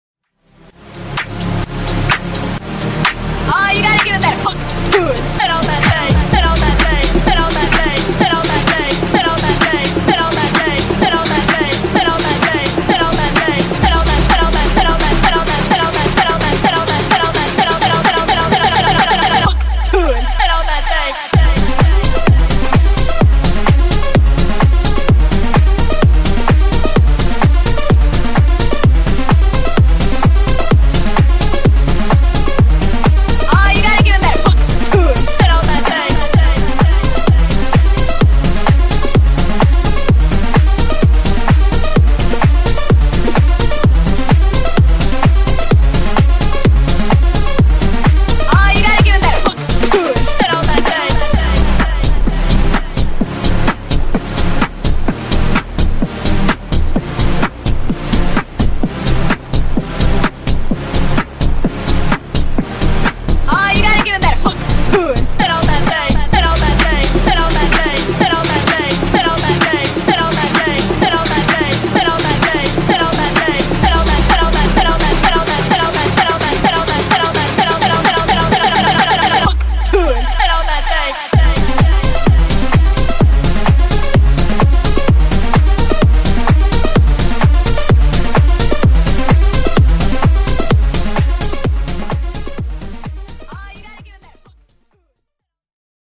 Genres: BOOTLEG , DANCE , TOP40
Clean BPM: 128 Time